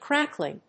音節cráck・ling 発音記号・読み方
/ˈkræklɪŋ(米国英語)/